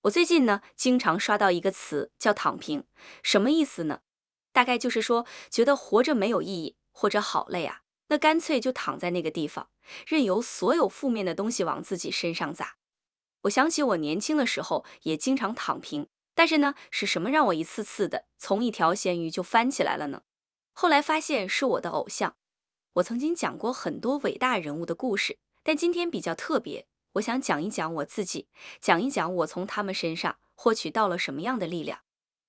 • 能够精准模拟真人的讲话语气、停顿等细节
以下是三种场景下真人音色与AIGC音色的对比：
知识分享_AIGC
知识分享_AIGC_demo.wav